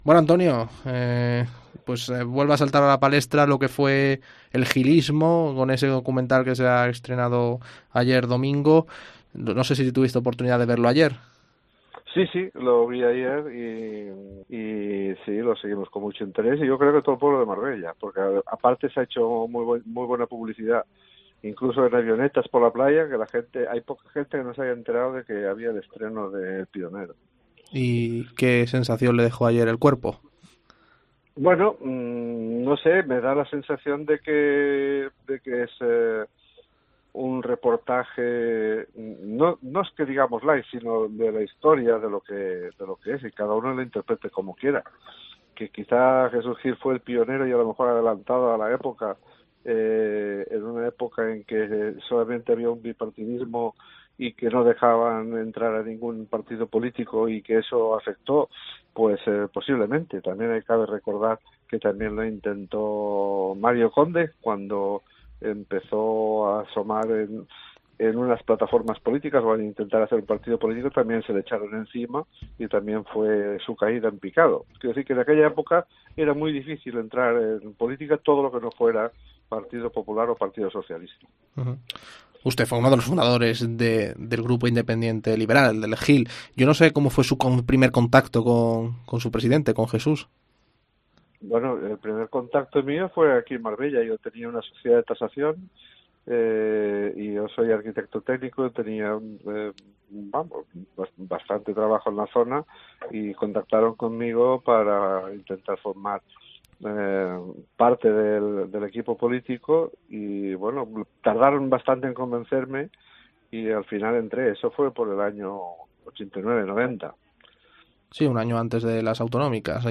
Entrevista con Antonio Sampietro, el primer concejal de Urbanismo de Gil en el Ayuntamiento de Marbella